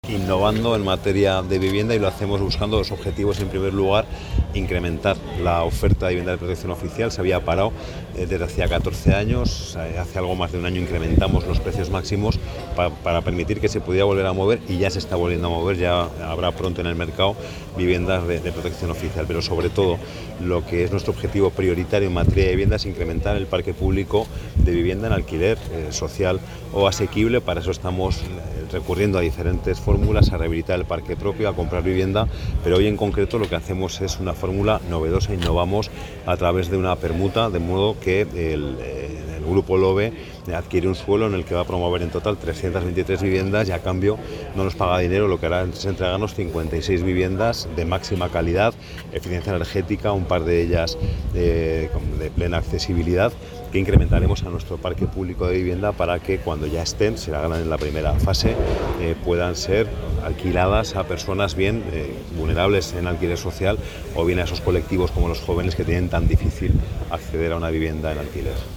José Luis Soro: Modelo de permuta para incorporar nuevas viviendas